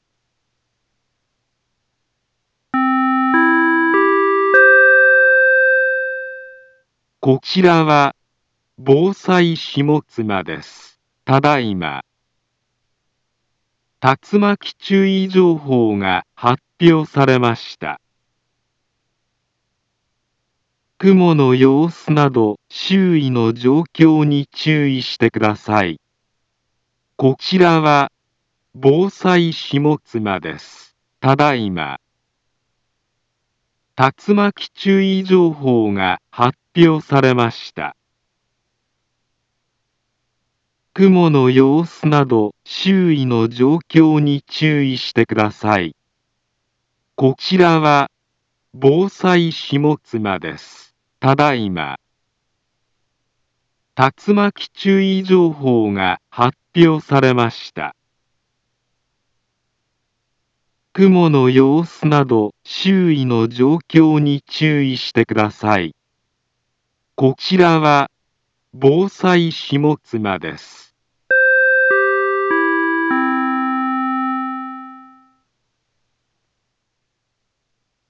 Back Home Ｊアラート情報 音声放送 再生 災害情報 カテゴリ：J-ALERT 登録日時：2023-08-04 16:15:09 インフォメーション：茨城県南部は、竜巻などの激しい突風が発生しやすい気象状況になっています。